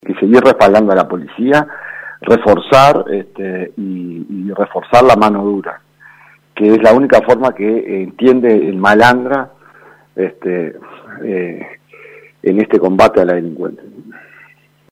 Escuche las declaraciones del representante del Partido Nacional: